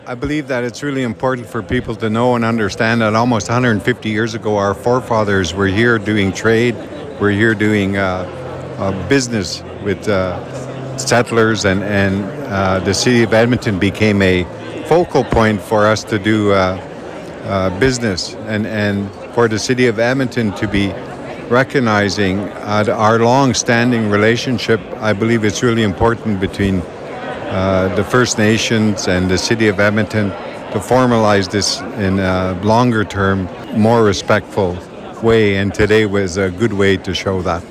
Chief Arcand says he believes that after the signing of Treaty Six 150 years ago, for the City of Edmonton to recognize the relationship with Treaty Six Nations is vital for a longer term and a more respectful way with the ceremony at City Hall being a prime example of showing respect.